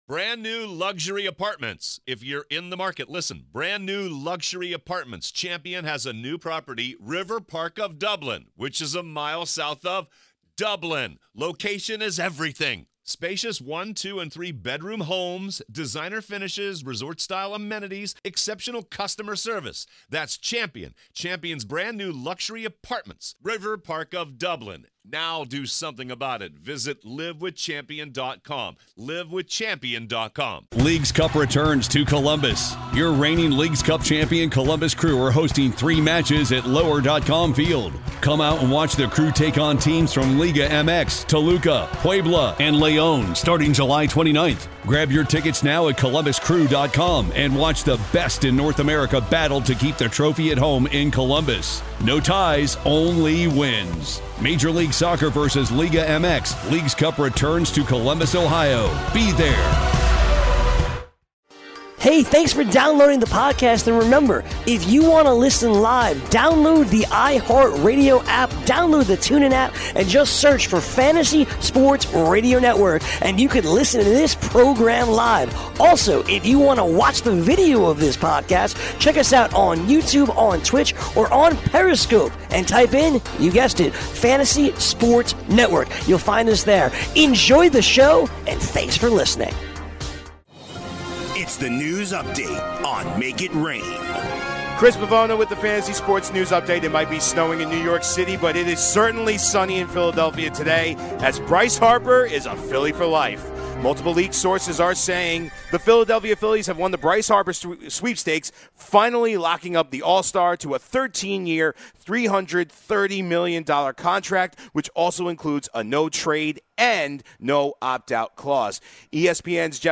live from the NFL Combine